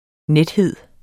Udtale [ ˈnεdˌheðˀ ]